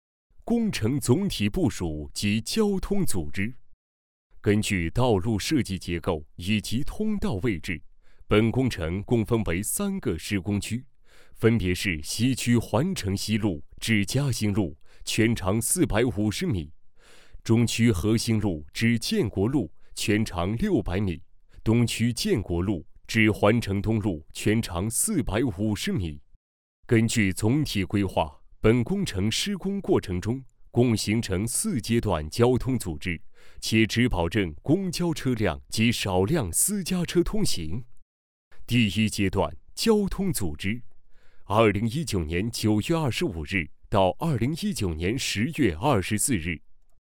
大气浑厚 规划总结配音
稳重大气男音，擅长专题解说、企业解说、人物解说、讲述等题。